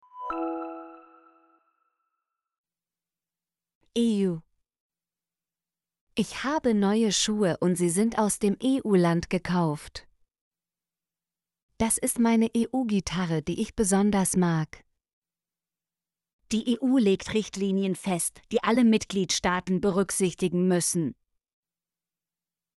eu - Example Sentences & Pronunciation, German Frequency List